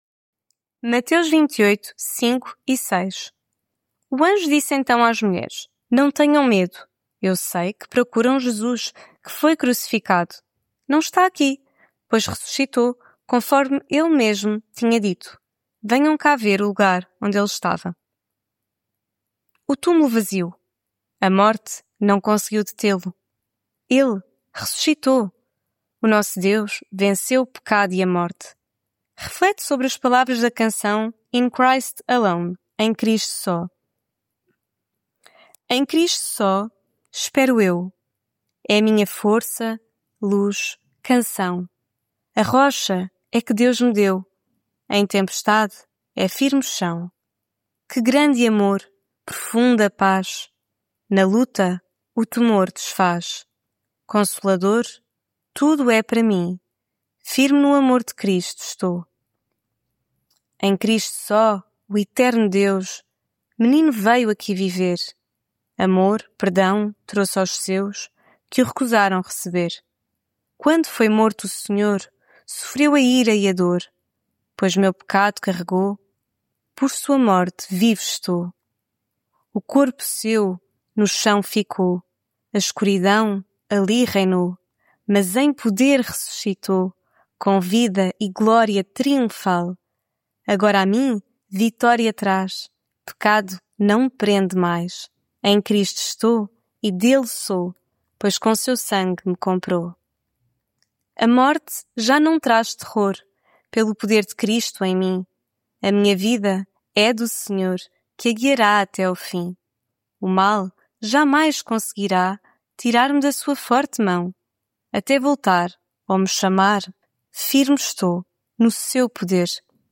Devocional Quaresma